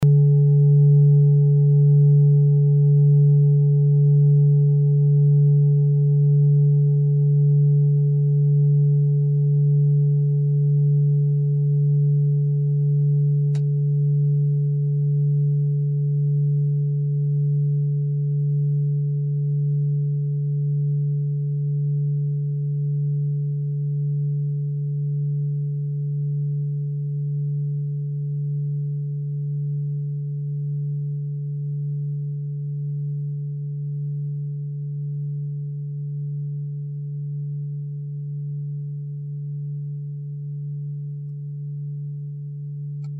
Klangschalen-Typ: Bengalen
Klangschale Nr.3
(Aufgenommen mit dem Filzklöppel/Gummischlegel)
klangschale-set-2-3.mp3